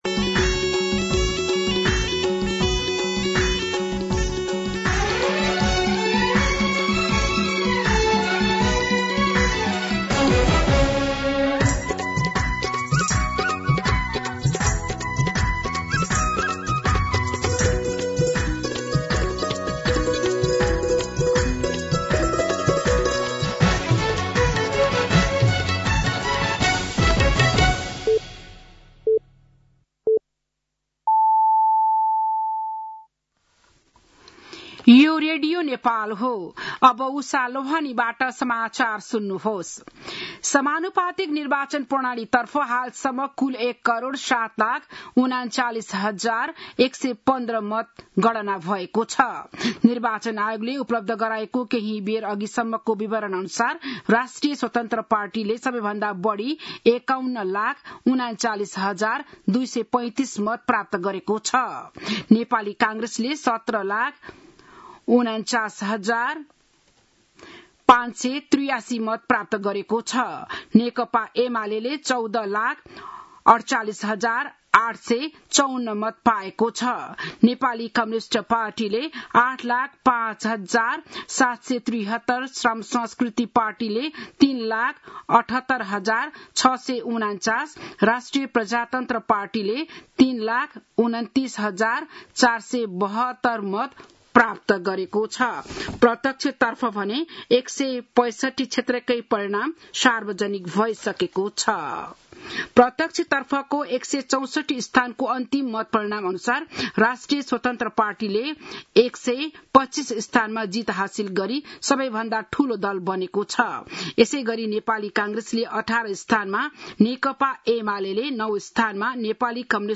बिहान ११ बजेको नेपाली समाचार : २७ फागुन , २०८२
11am-News-27.mp3